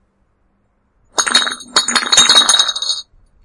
TDK Sound " 铿锵的瓶子
描述：3个牛奶瓶在一起叮当作响的声音。
标签： 奶粉 奶瓶 玻璃
声道立体声